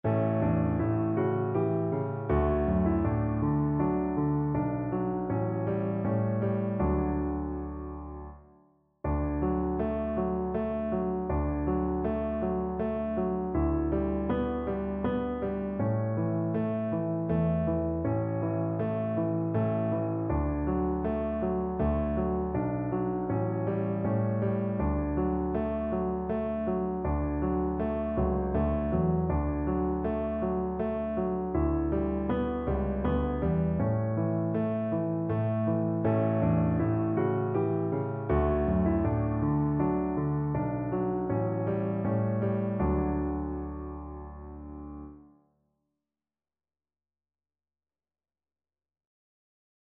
Cello
3/4 (View more 3/4 Music)
D major (Sounding Pitch) (View more D major Music for Cello )
Andante
Traditional (View more Traditional Cello Music)
world (View more world Cello Music)
Israeli